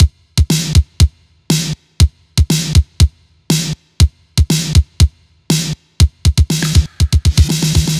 Bp Bd _ Snr Loop.wav